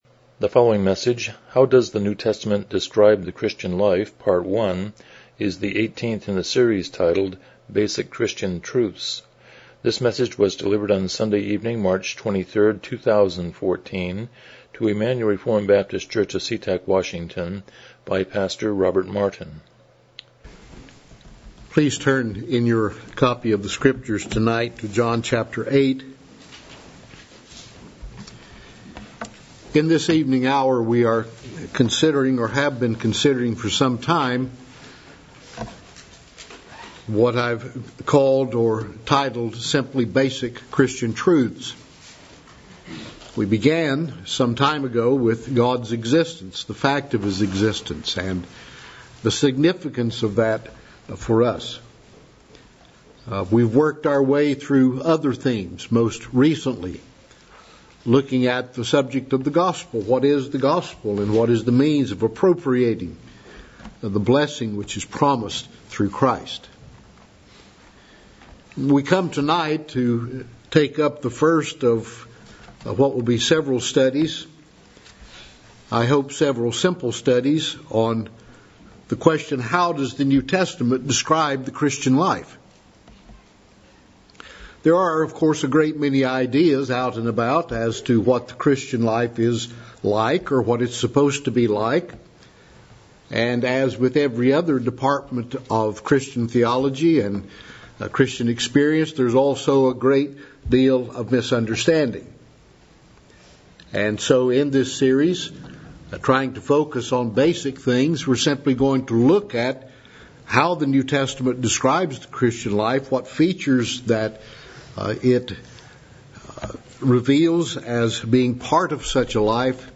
Basic Christian Truths Service Type: Evening Worship « 50 The Sermon on the Mount